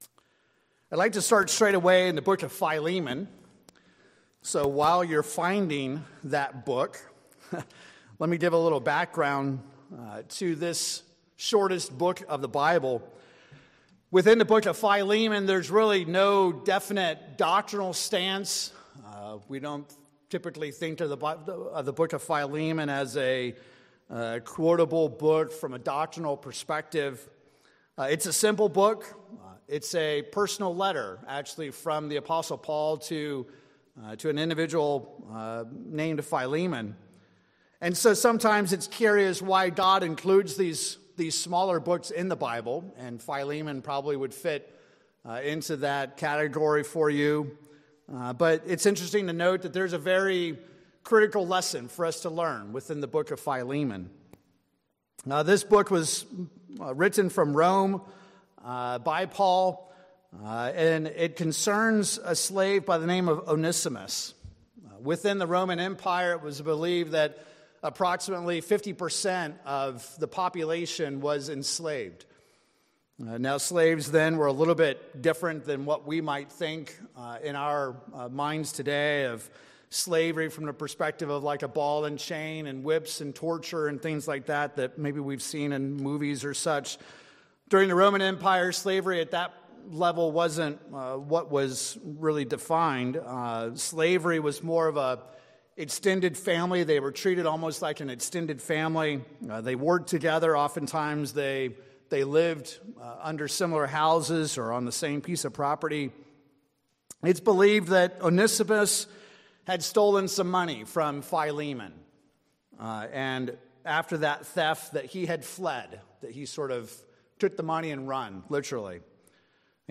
In this sermon we will look at mercy from God's perspective through three Biblical examples.